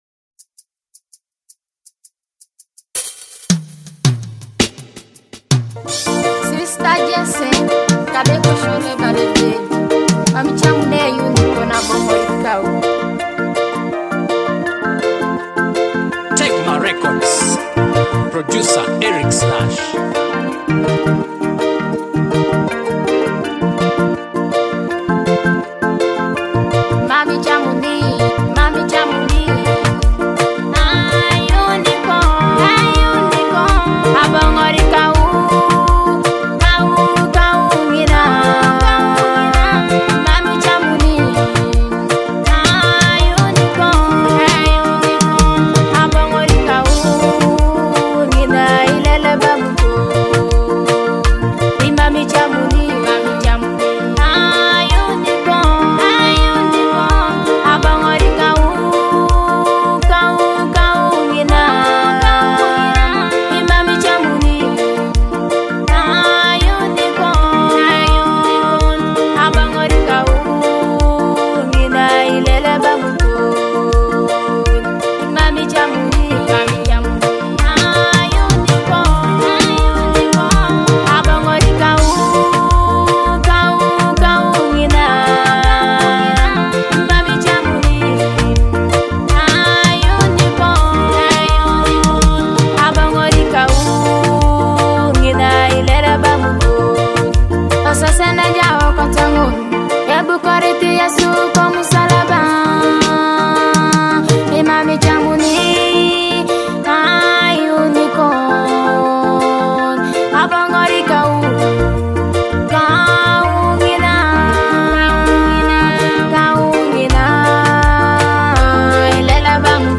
With uplifting lyrics and soulful vocals